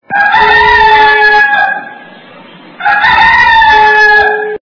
» Звуки » Природа животные » Петух - кукарекание
При прослушивании Петух - кукарекание качество понижено и присутствуют гудки.
Звук Петух - кукарекание